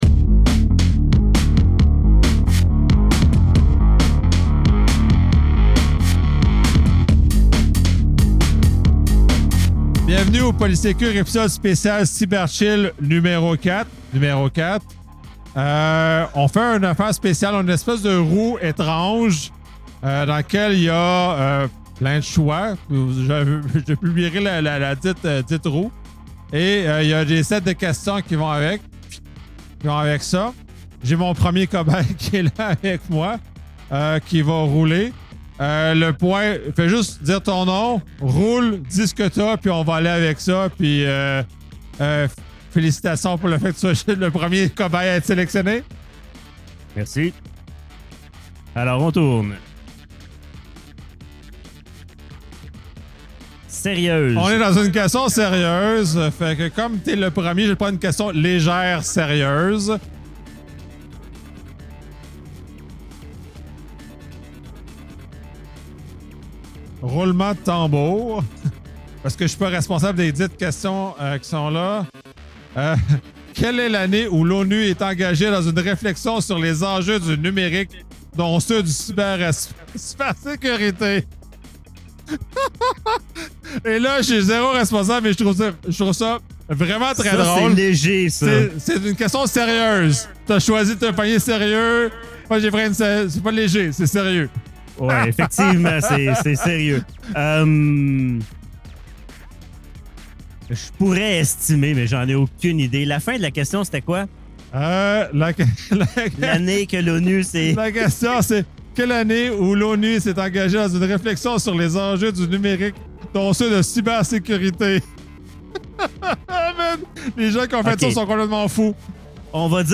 Ce podcast présente un format ludique où différents invités viennent répondre à des questions tirées au hasard grâce à une “roue” qui détermine la nature des questions (sérieuses ou drôles). L’animation est décontractée et les participants, qualifiés de “cobails” (cobayes), sont sélectionnés successivement par le précédent participant.